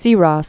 (sērôs)